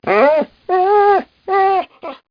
1 channel
dogwhn04.mp3